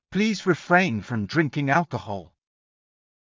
ﾌﾟﾘｰｽﾞ ﾘﾌﾚｲﾝ ﾌﾛﾑ ﾄﾞﾘﾝｷﾝｸﾞ ｱﾙｺﾎｰﾙ
alcohol は「アルコール」ですが、発音が日本語とは違います。